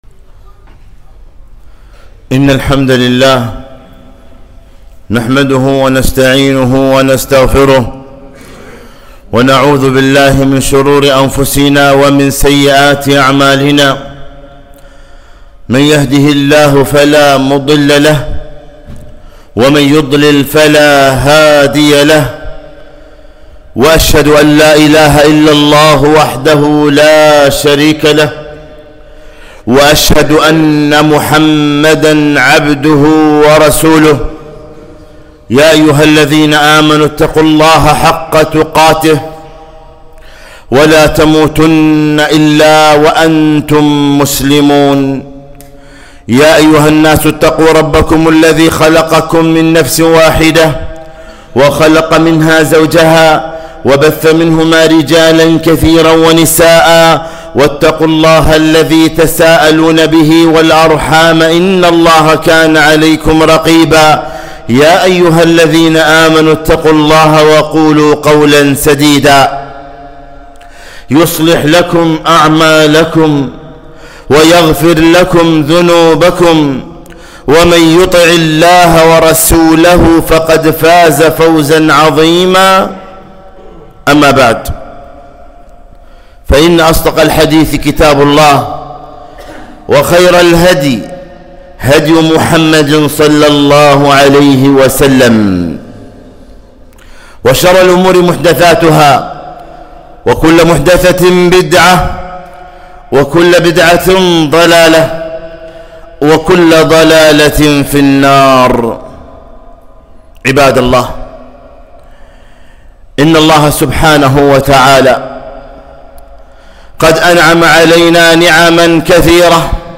خطبة - احذر الكسب الحرام